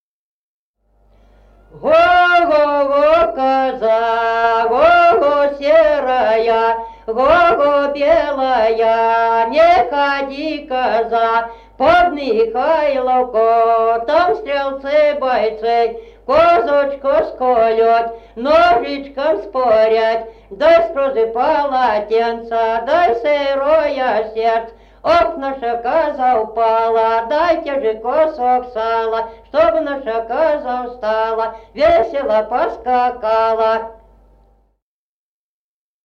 Народные песни Стародубского района «Го-го-го, коза», новогодняя щедровная.
1953 г., д. Камень.